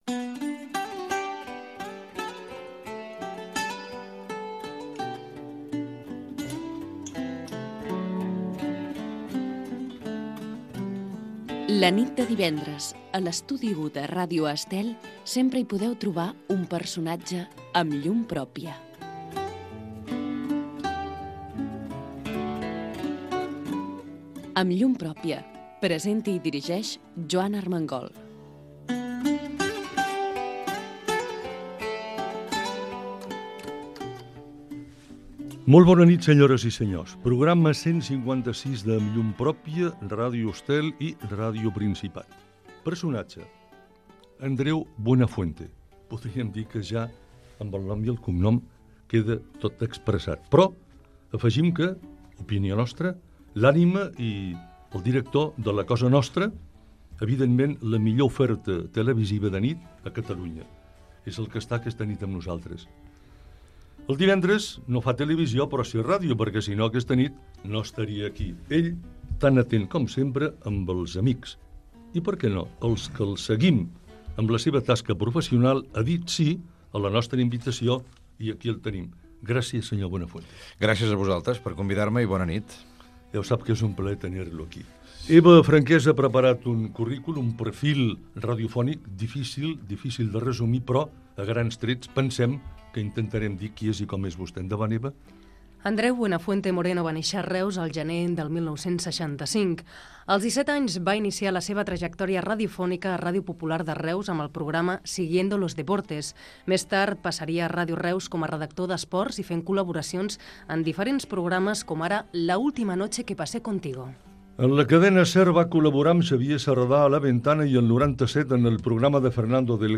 Careta del programa
Entrevista al presentador Andreu Buenafuente
Entreteniment